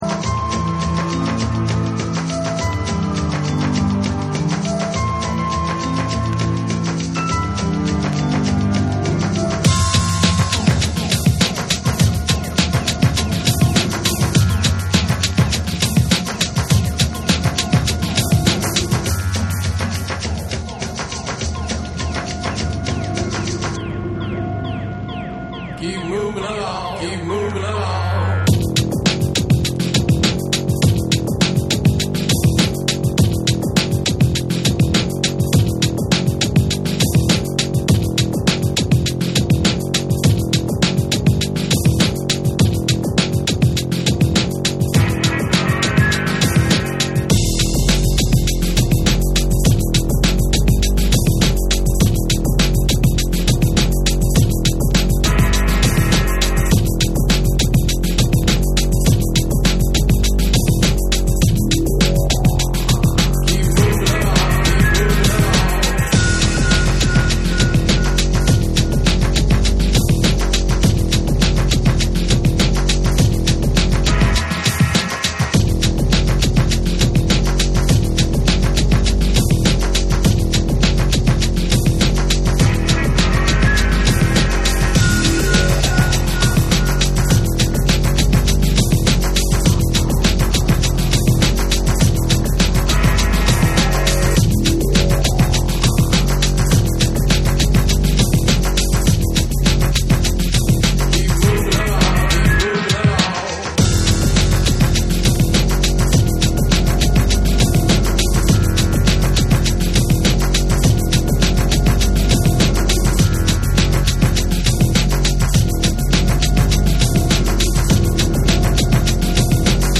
深海を思わせる重厚なベースとダビーな音像が印象的なダウンテンポ／トリップホップ
ロウでスモーキーな質感の中にセンスが光る、隠れた好盤！